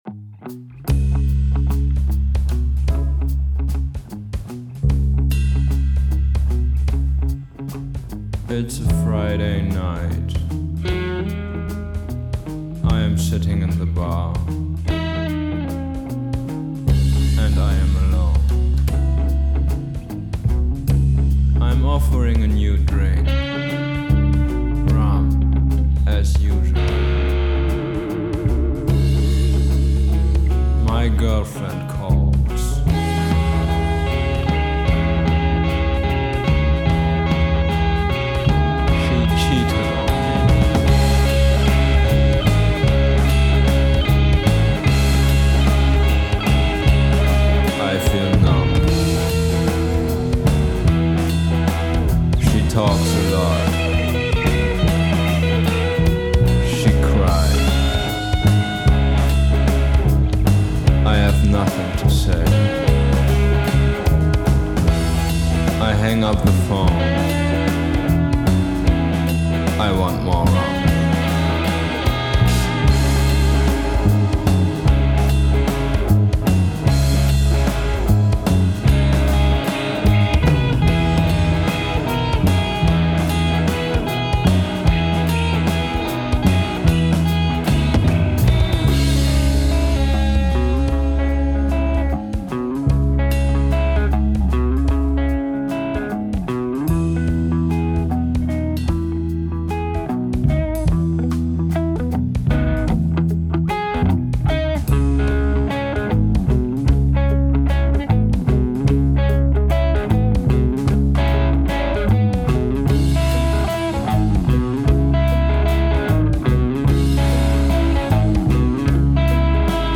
Spoken Word Rock Song - Was noch verbessern/angehen?
Mit dem Mix bin ich eigentlich schon recht zufrieden, er soll ungern zu überproduziert / klinisch klingen aber er sollte auch genug Punch haben um den Körper (ein bisschen) in Schwingung zu bringen.
Bisher habe ich vor allem versucht die Lautstärke zu automatisieren, viel hin und her gepannt, Gitarren rein und rausspringen lassen damit das ganze schön dynamisch ist...
60er/70er Jahre (Psychedelic) Rock
also irgendwo zwischen Lo-Fi und Fetten Gitarren